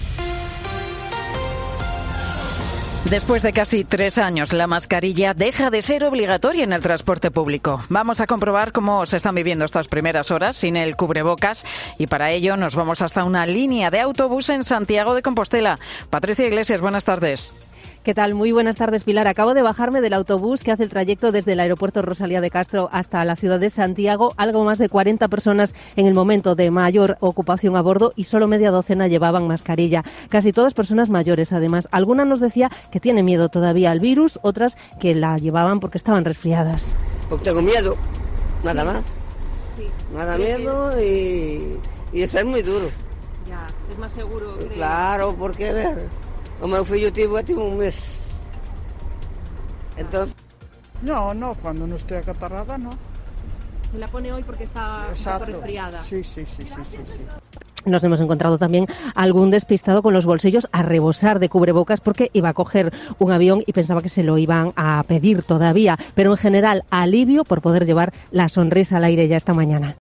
A lo largo de la mañana, COPE también se ha acercado a una línea de autobús que cubre el trayecto que une el aeropuerto Rosalía de Castro con la ciudad de Santiago de Compostela.